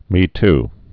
(mēt)